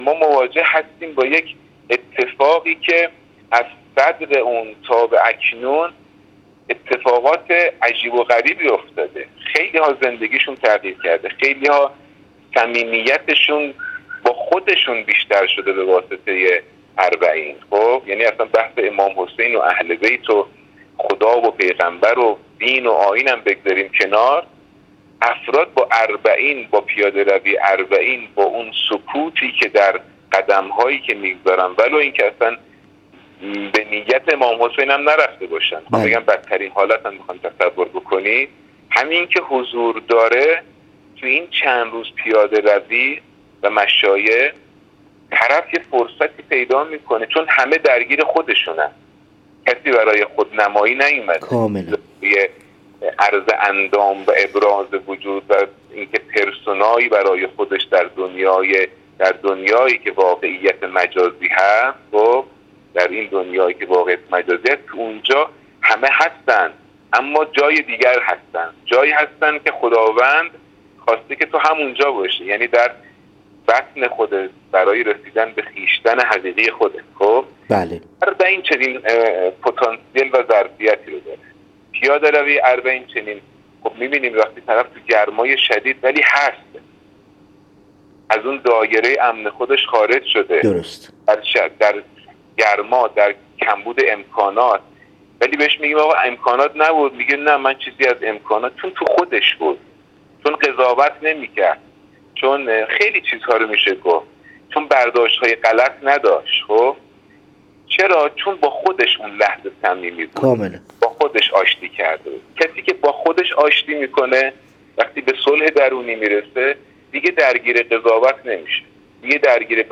هنرمند عکاس در گفت‌وگو با ایکنا: